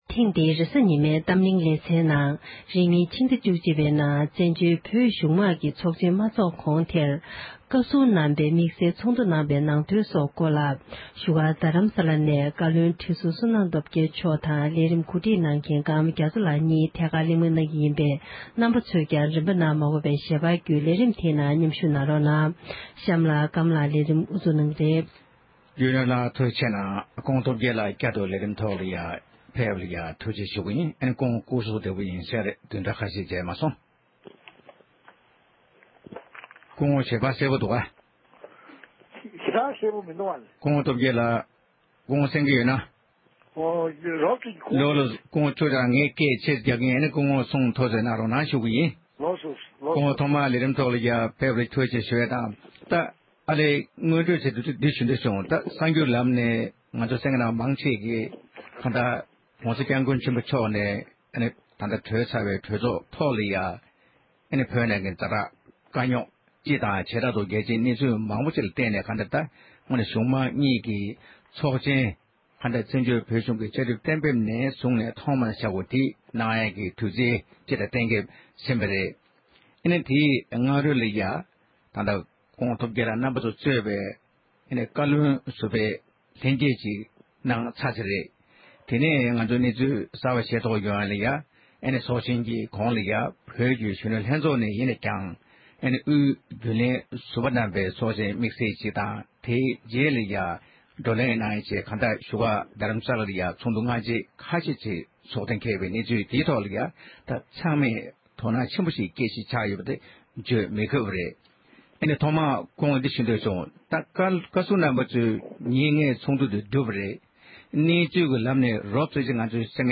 བགྲོ་གླེང་